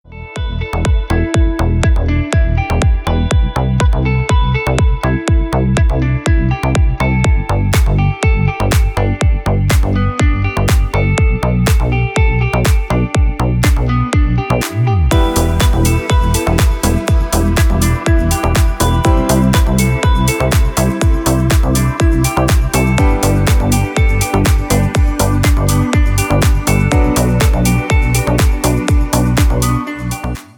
Лайтовый трек для рингтона